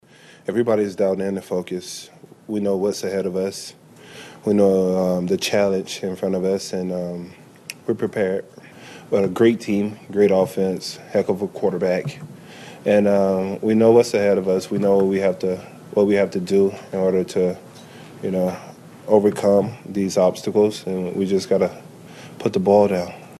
Defensive lineman Chris Jones says they know what lies ahead.